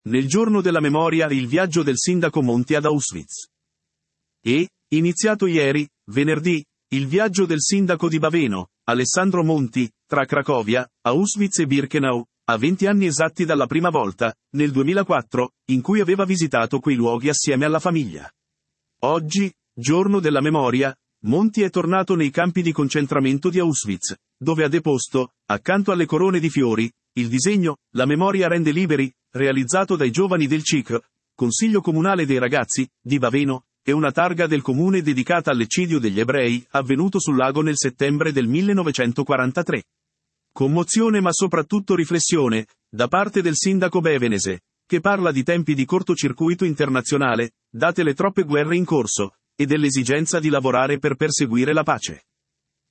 Commozione ma soprattutto riflessione, da parte del sindaco bavenese, che parla di tempi di cortocircuito internazionale, date le troppe guerre in corso, e dell’esigenza di lavorare per perseguire la pace.